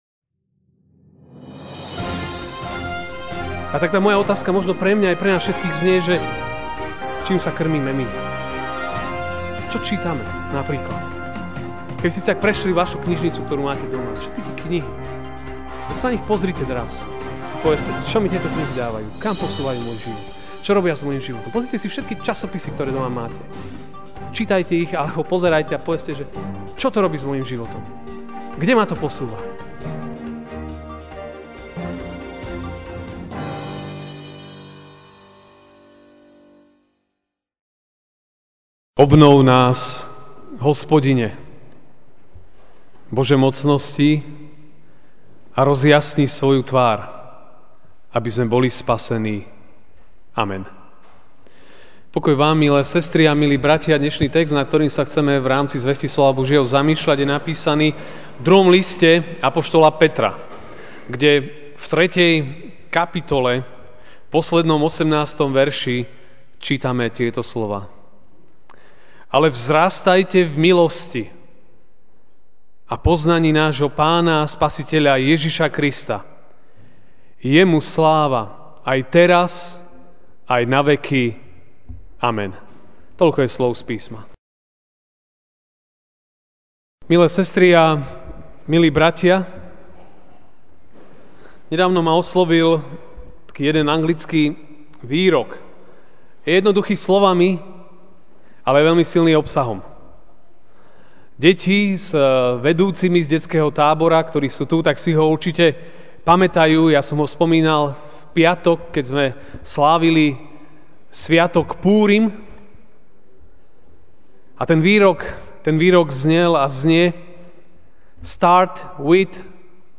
júl 16, 2017 S Pánom Ježišom začať-zostávať-končiť MP3 SUBSCRIBE on iTunes(Podcast) Notes Sermons in this Series Ranná kázeň: S Pánom Ježišom začať-zostávať-končiť (2. Pt. 3, 18) ... ale vzrastajte v milosti a poznaní nášho Pána a Spasiteľa Ježiša Krista.